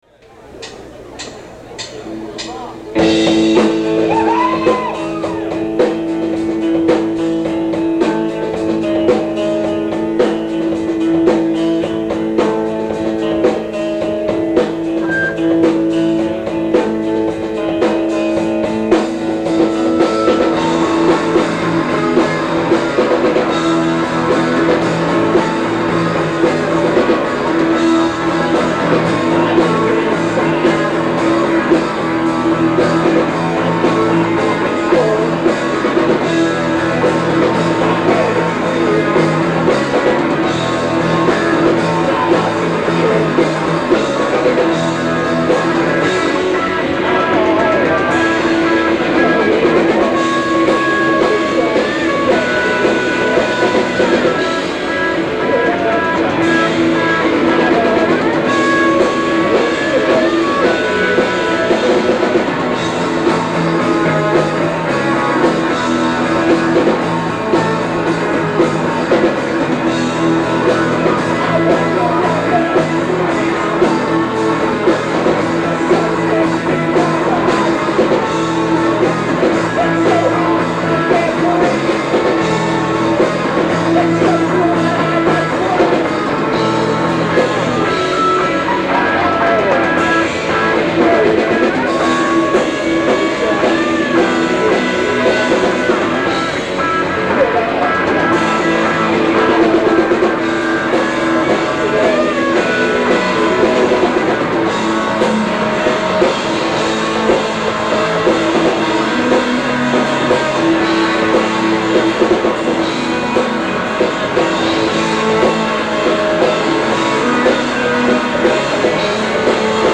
live at the Woodstock Bar
Montreal, Quebec, Canada